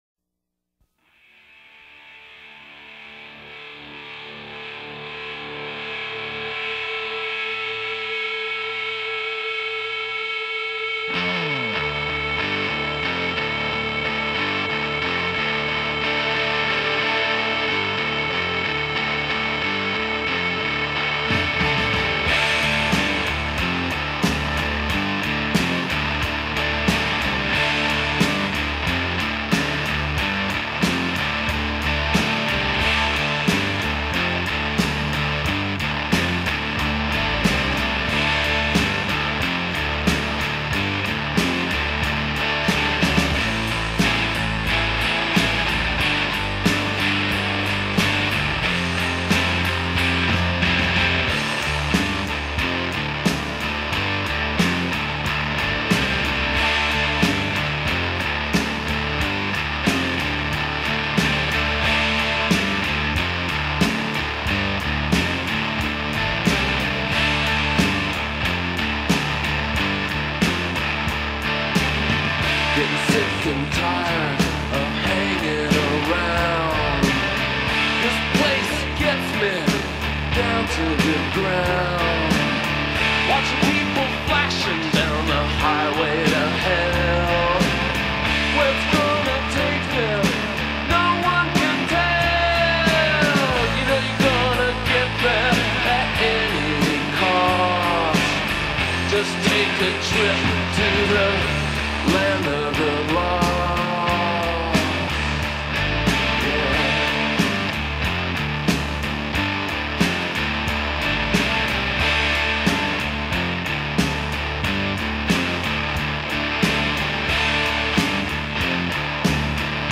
heavier and riffier